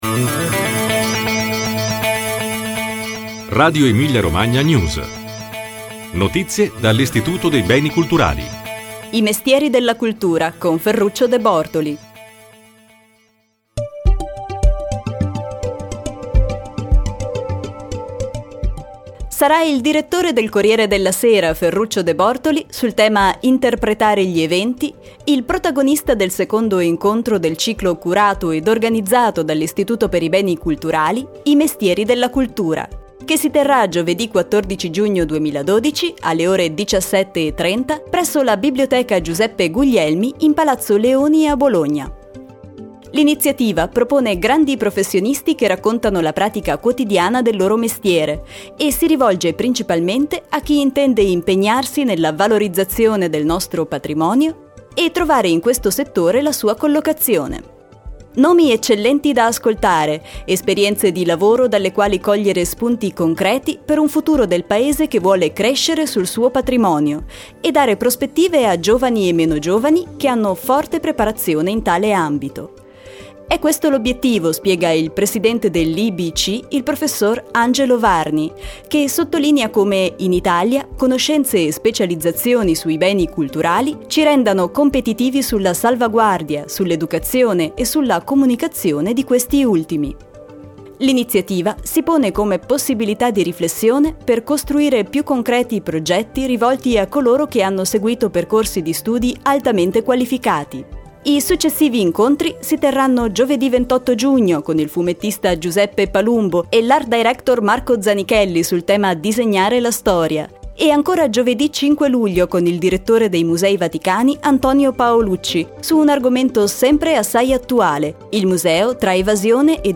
Incontro con il direttore del “Corriere della Sera” sul tema “Interpretare gli eventi”